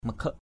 /mə-kʱəʔ/